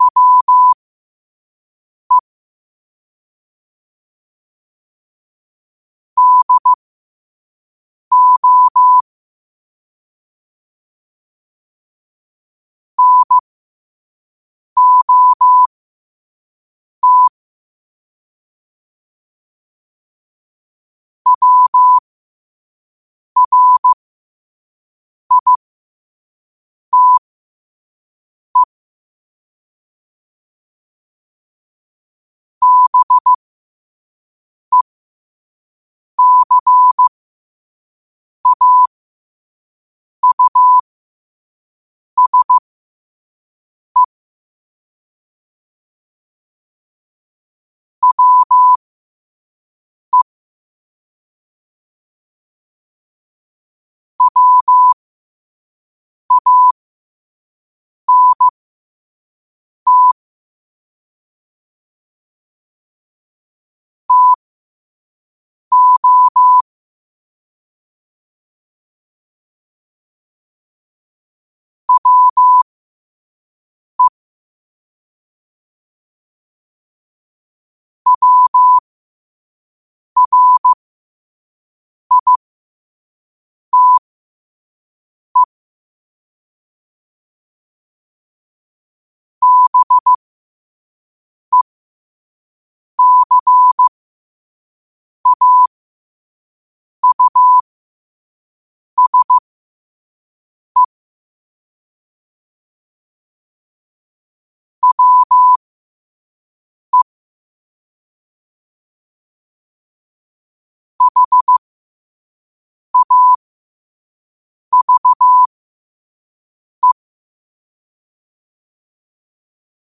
New quotes every day in morse code at 5 Words per minute.